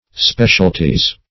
Speciality \Spe`ci*al"i*ty\, n.; pl. Specialities. [See